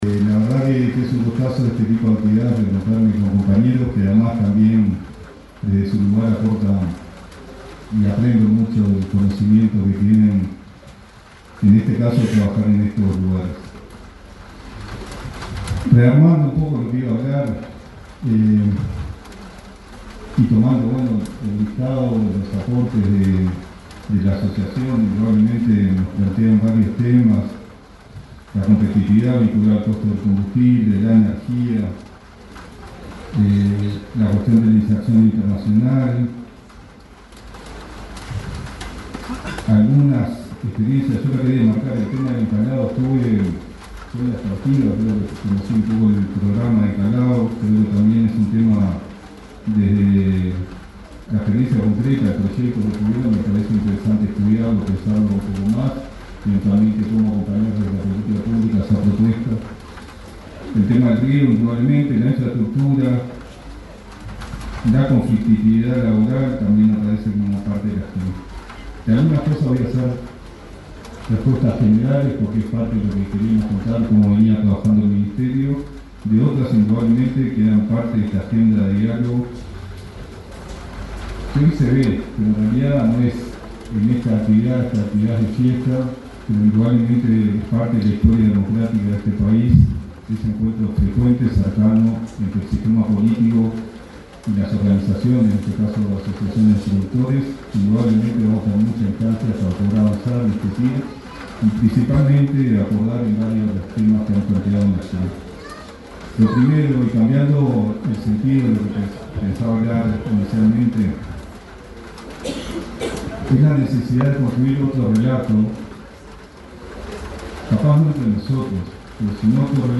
Palabras del ministro interino de Ganadería, Matías Carámbula
Al participar de la ceremonia inaugural de la quinta cosecha de arroz, en Dolores, departamento de Soriano, expresó un discurso el ministro interino